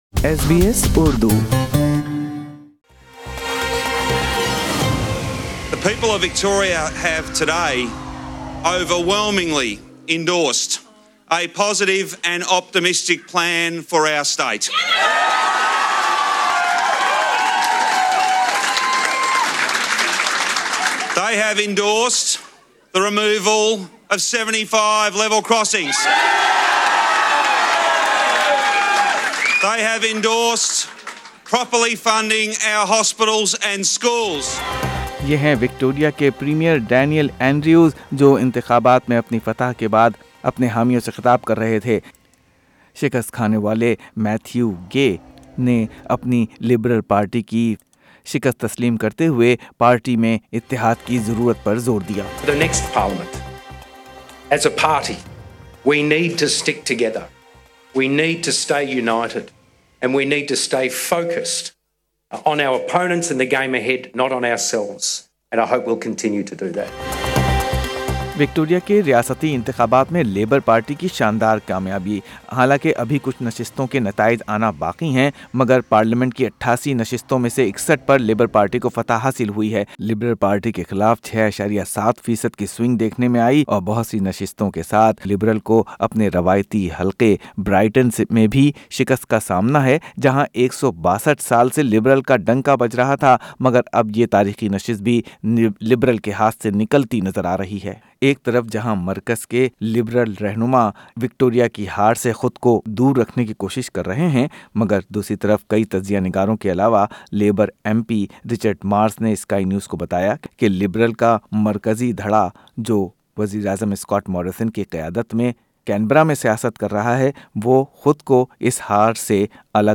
Dr Ali khan shared his views about ALP victory, Liberal humiliated defeat and improved performance of micro parties. Here is Dr Ali khan in conversation with SBS Urdu Past Stories